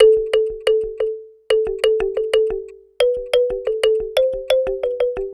Kalimba.wav